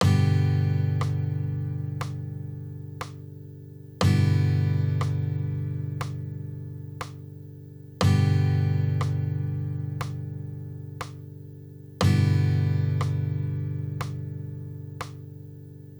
The audio samples that follow each pair will sound out the traditional chord first followed by the easy version.
G and G easy chords
gmaj-easy.wav